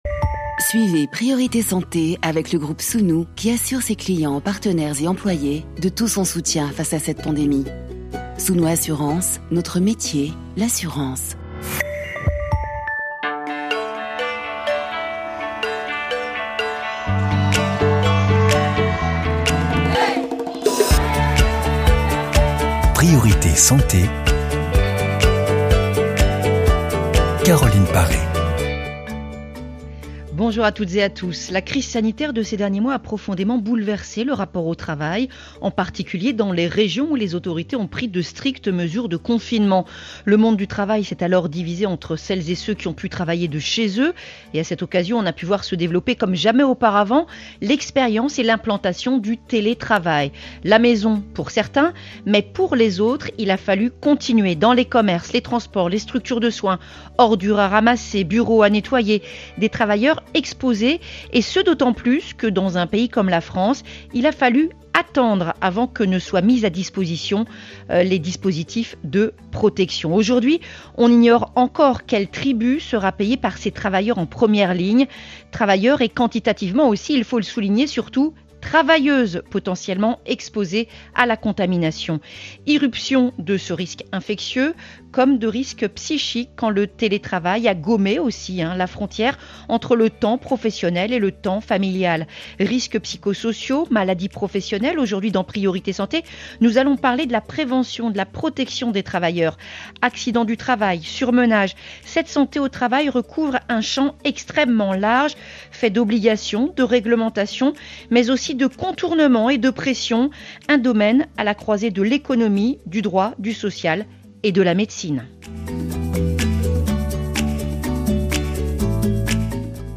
Dans son édition du 10 juin 2020, l’émission « Priorité Santé » de Radio France Internationale (RFI) a reçu d’imminents spécialistes de la Santé du travail autour du thème « Santé au travail» , dont :